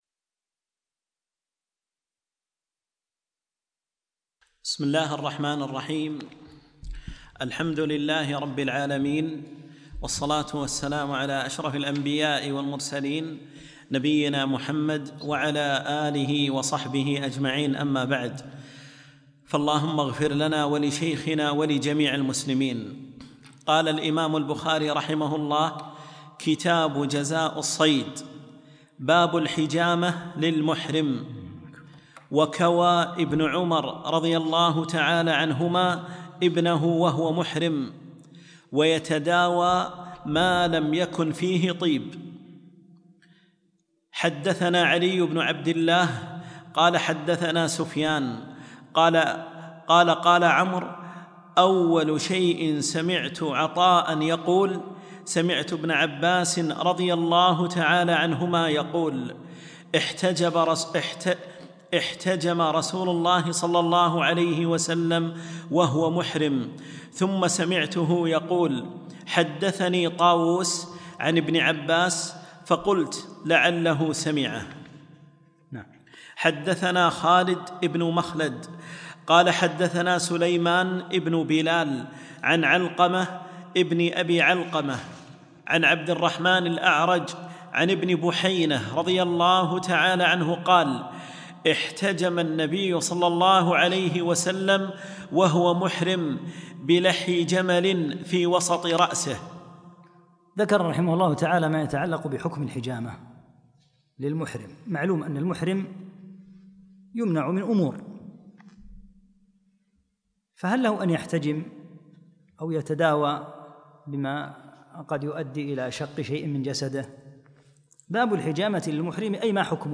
3- الدرس الثالث